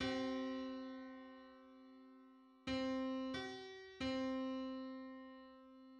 Just: 93/64 = 646.99 cents.
Public domain Public domain false false This media depicts a musical interval outside of a specific musical context.
Ninety-third_harmonic_on_C.mid.mp3